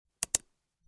SPACEBAR.mp3